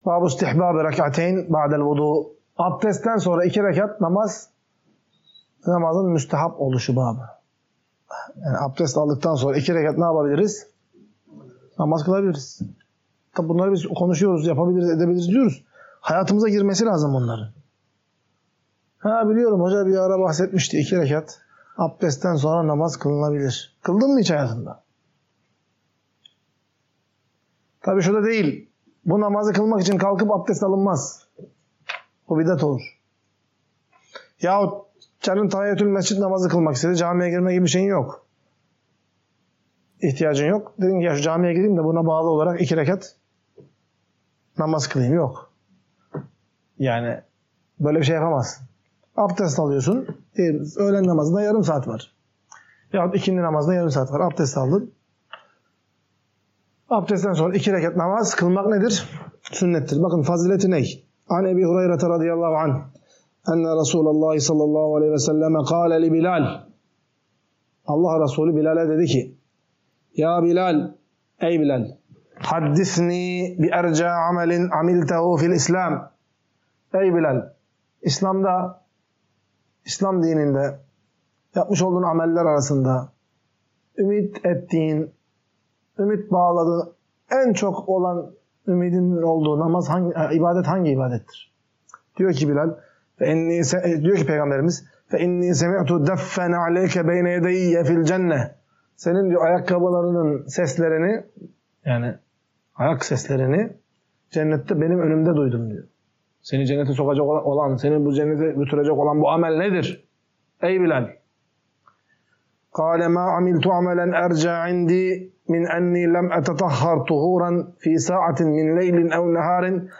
Ders - 30.